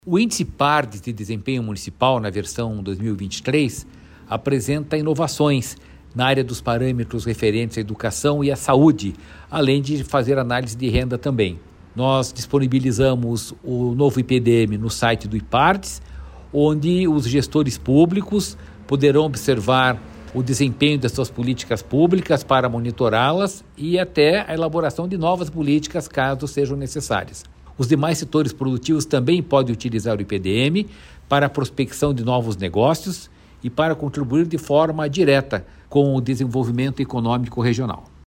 Sonora do presidente do Ipardes, Jorge Callado, sobre os dados do Índice Ipardes de Desempenho Municipal